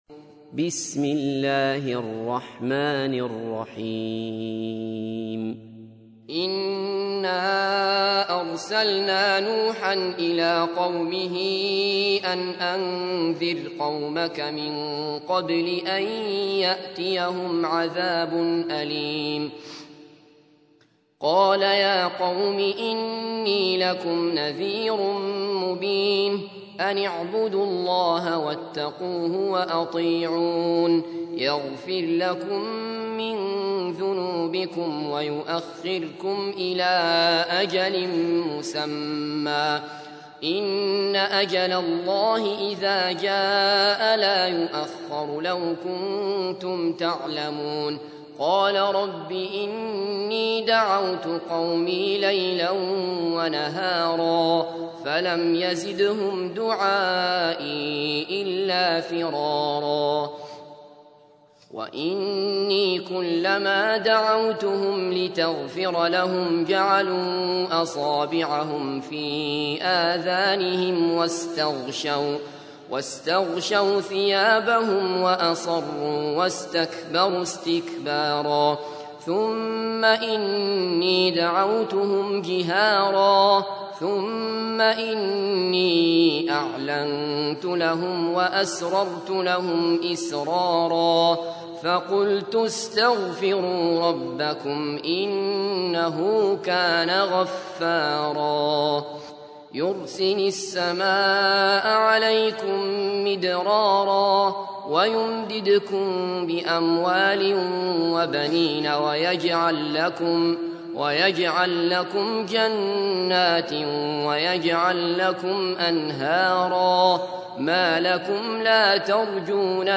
تحميل : 71. سورة نوح / القارئ عبد الله بصفر / القرآن الكريم / موقع يا حسين